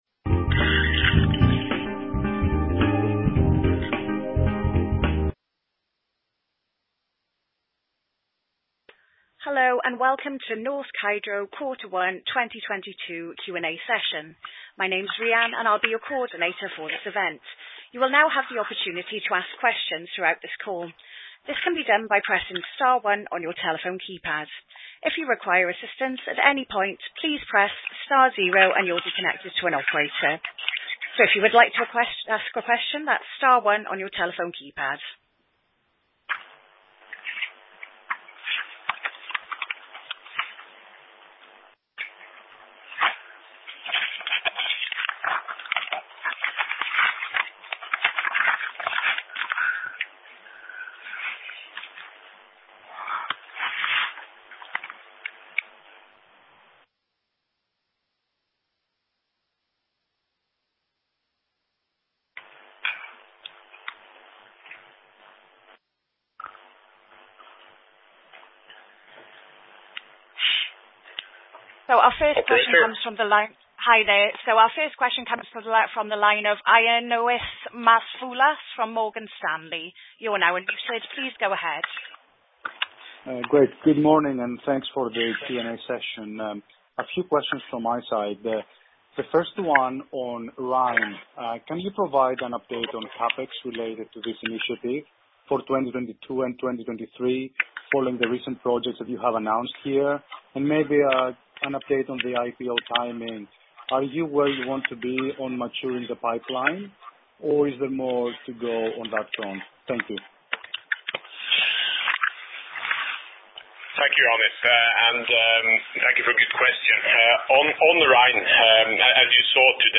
q1-2022-qa-conference-call.mp3